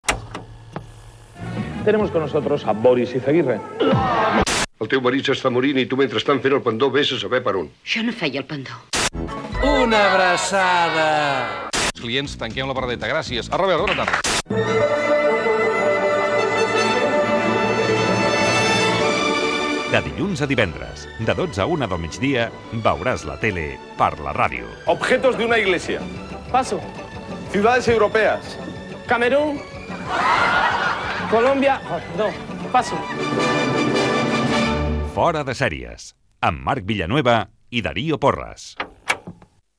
Promoció del programa sobre la televisió